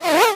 bea_reload_01.ogg